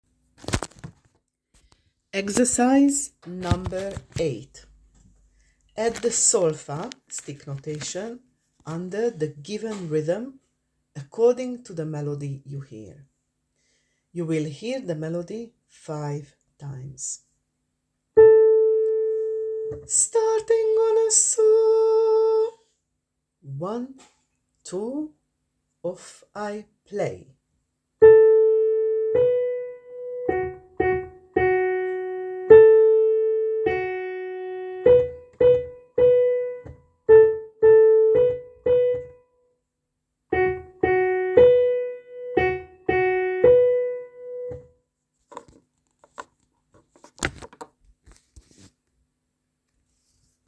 8.Add the solfa (stick notation) under the given rhythm according to the melody you hear. You will hear the melody 5 times: